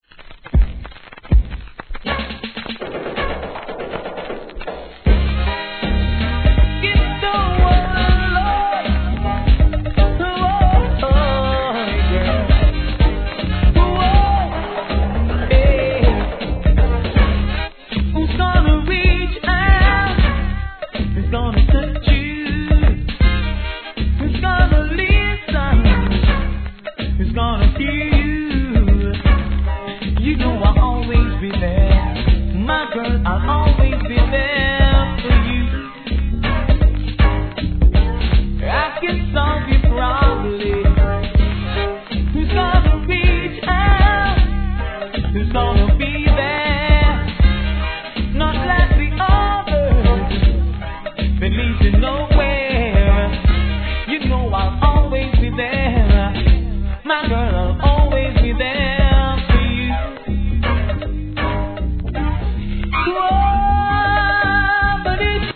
(序盤チリ入りますが落ち着きます)
REGGAE